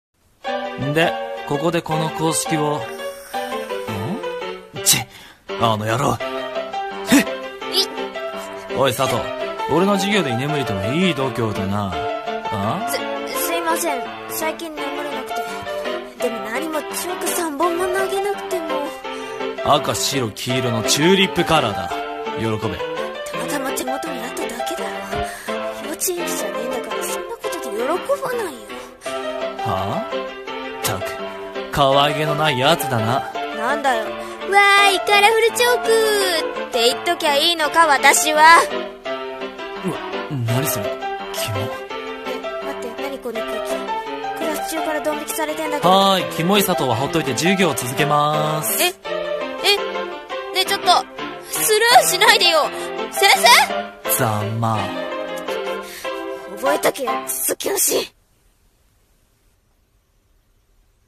【声劇台本】「居眠り」